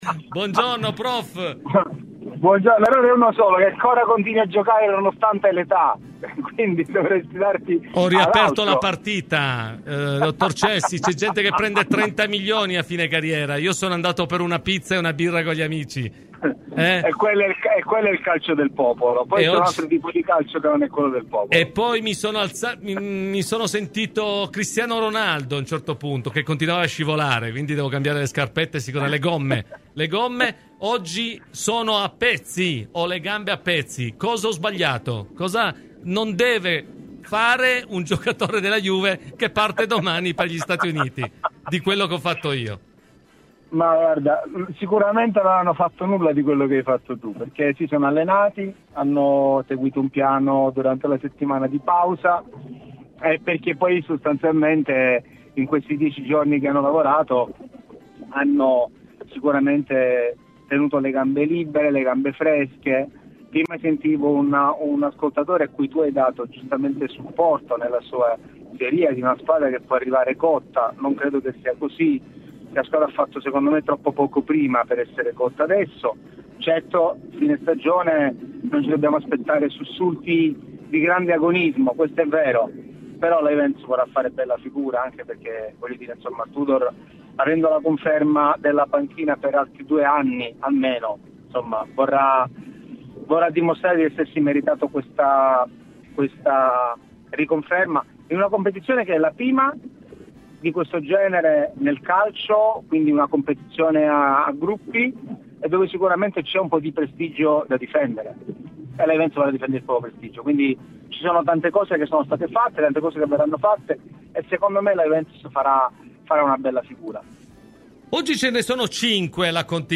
Intervenuto su RadioBianconera